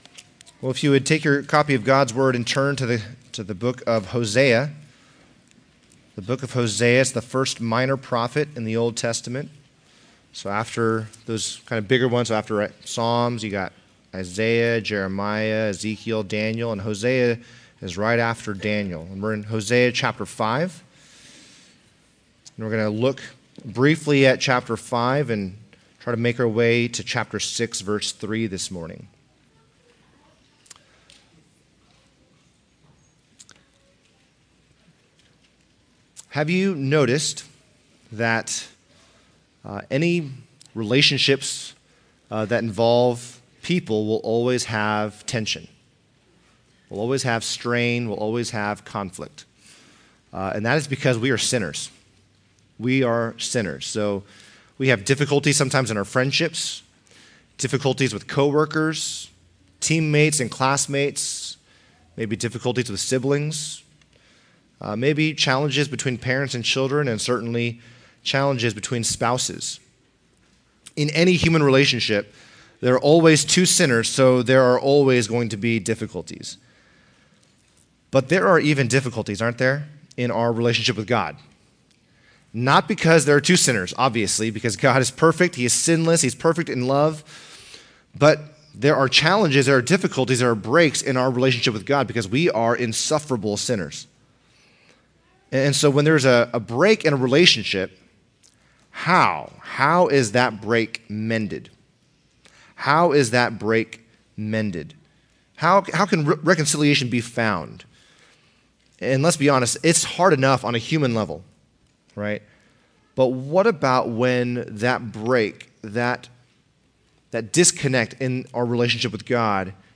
Sermons Archive - CGBC